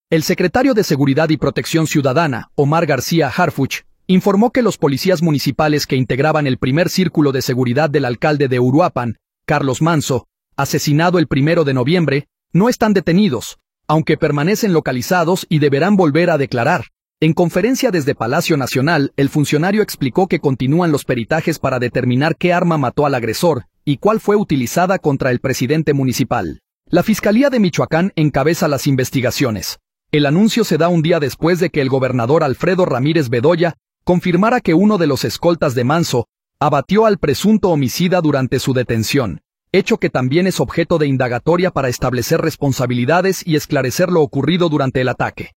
En conferencia desde Palacio Nacional, el funcionario explicó que continúan los peritajes para determinar qué arma mató al agresor y cuál fue utilizada contra el presidente municipal. La Fiscalía de Michoacán encabeza las investigaciones.